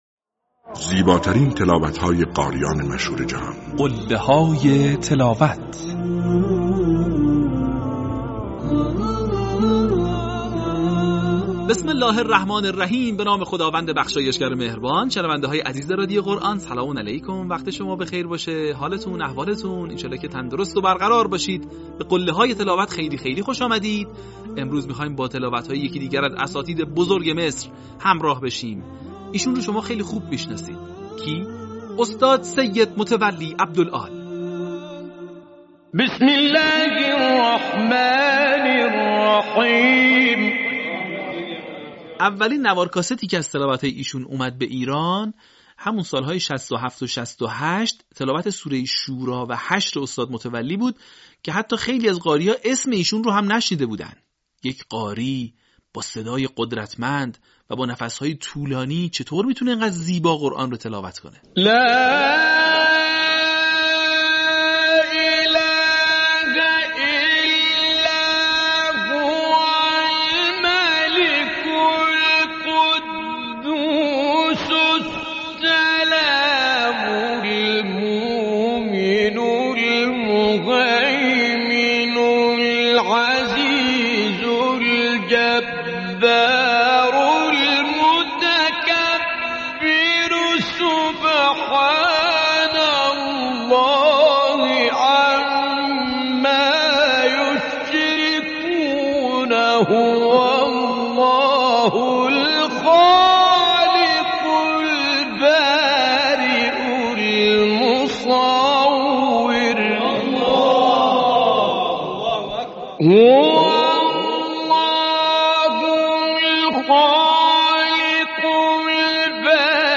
این مجموعه شامل تلاوت‌های ماندگار قاریان بین‌المللی مصری است که تاکنون 40 قسمت آن از ایکنا منتشر شده است.
قله‌های تلاوت سید متولی عبدالعال